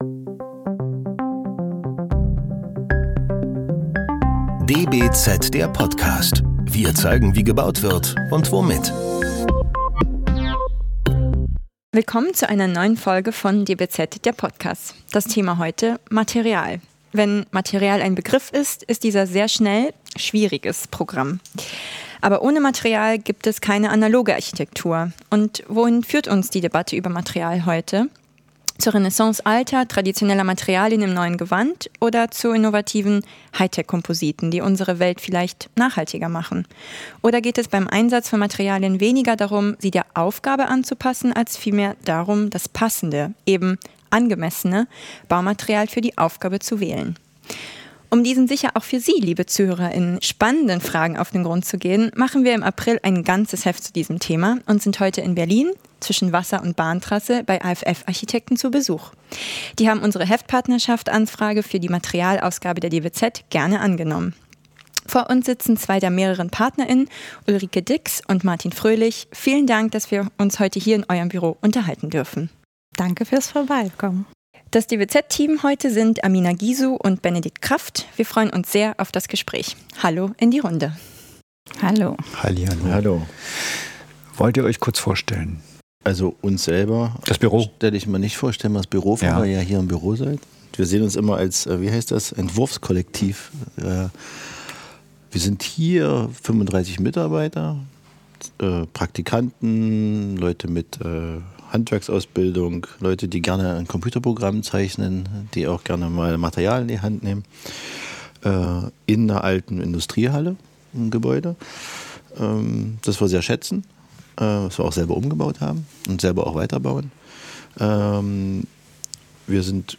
Für diesen Podcast sind wir zu AFF Architekten nach Berlin gefahren, die unsere Heftpartner für die aktuelle DBZ-Ausgabe sind.